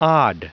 Prononciation du mot od en anglais (fichier audio)
Prononciation du mot : od